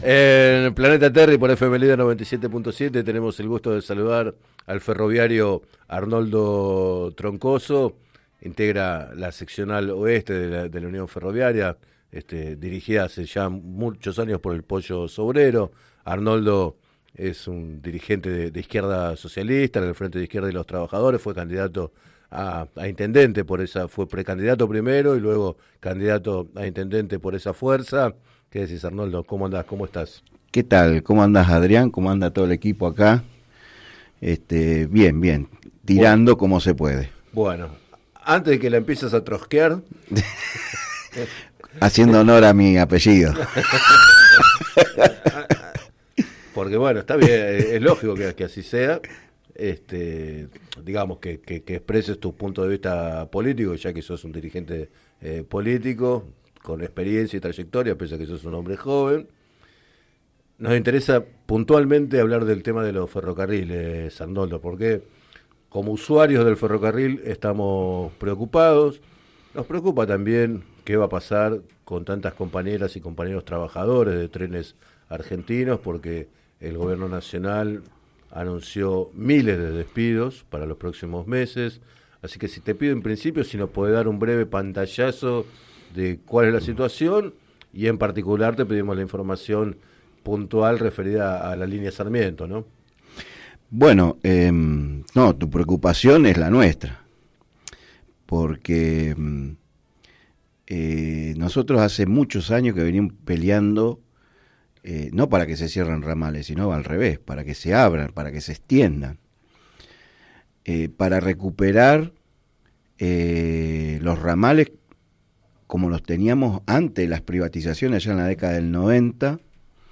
Entrevistado en el programa Planeta Terri de FM Líder